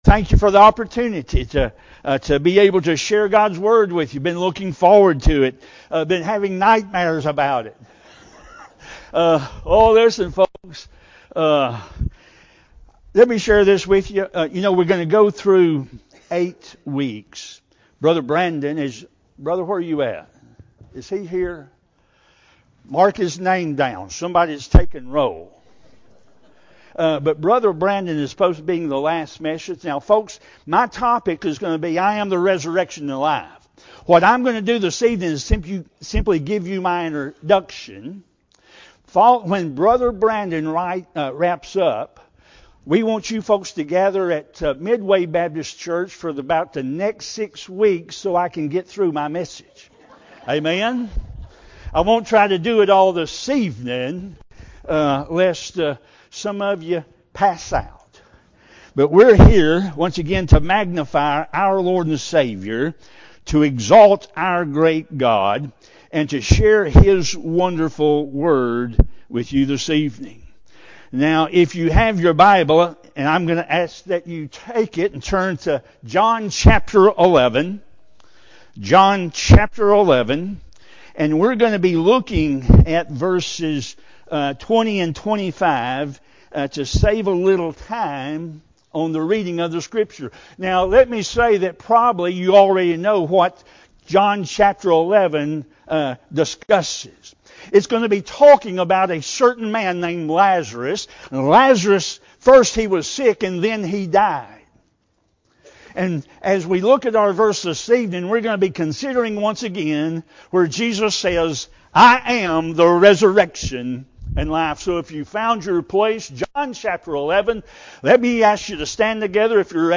The 4th out of 8 total sermons in our 2025 joint service series on the